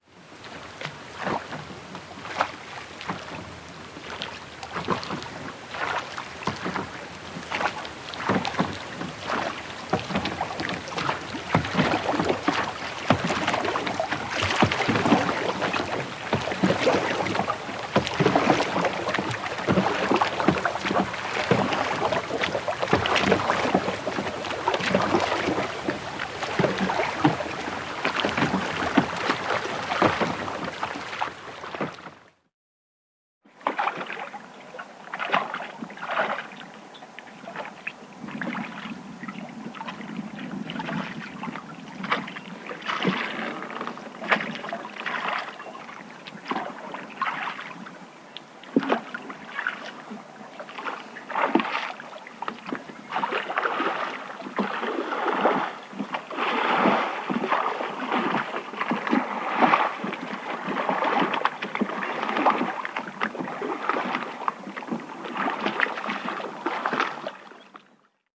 На этой странице собраны звуки весла: плеск воды, ритмичные гребки и другие умиротворяющие аудиоэффекты.
Звук гребли Плывем на вёсельной лодке